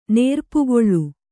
♪ nērpugoḷḷu